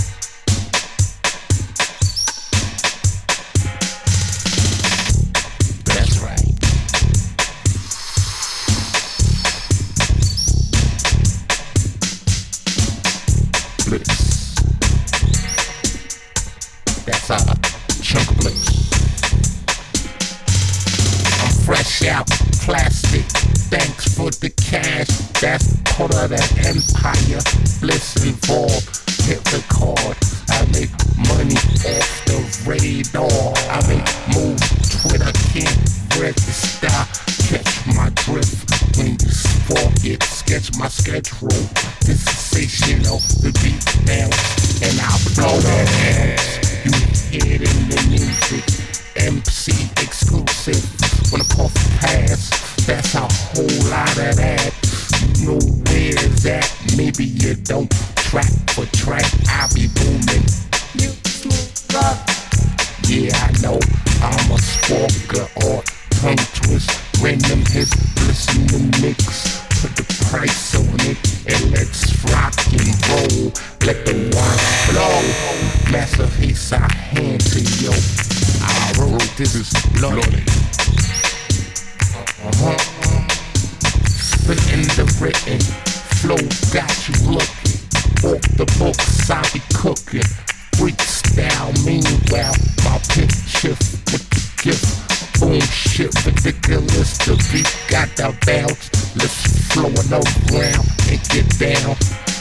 Remix集